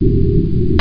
1 channel
physgun_loop3.mp3